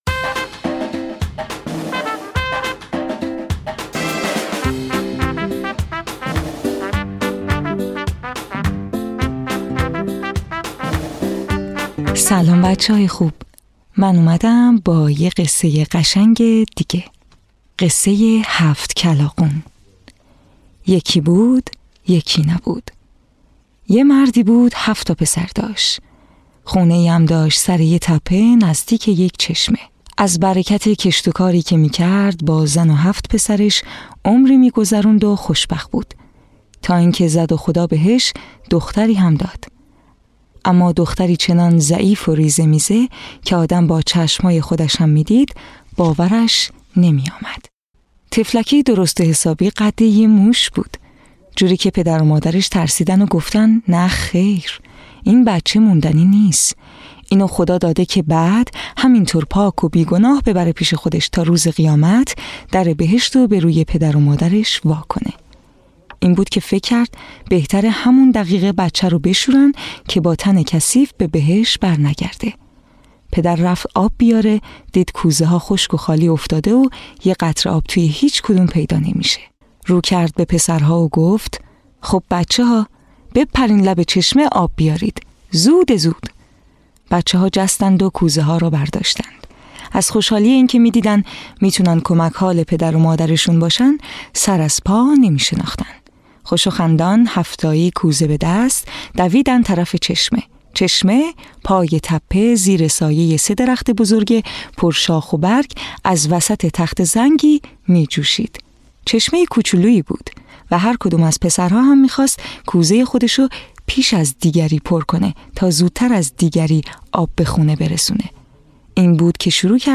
قصه های کودکانه صوتی- این داستان: هفت کلاغون
تهیه شده در استودیو نت به نت